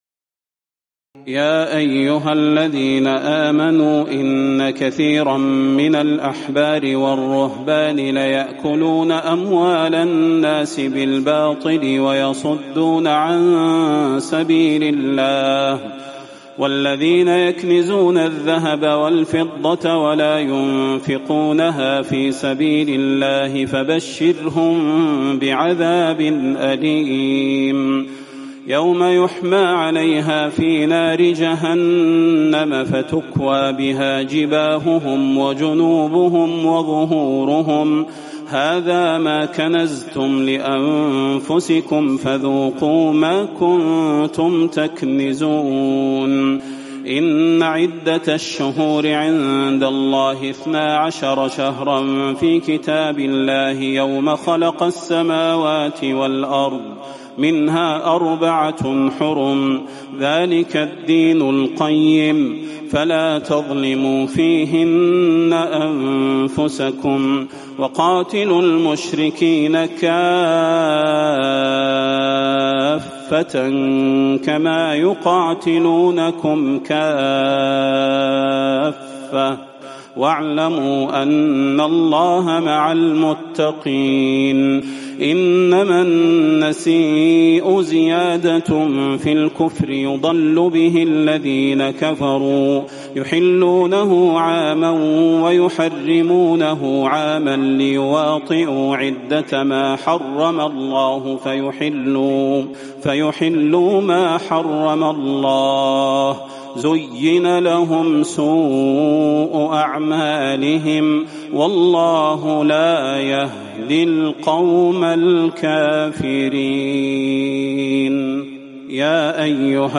تراويح الليلة العاشرة رمضان 1438هـ من سورة التوبة (34-89) Taraweeh 10 st night Ramadan 1438H from Surah At-Tawba > تراويح الحرم النبوي عام 1438 🕌 > التراويح - تلاوات الحرمين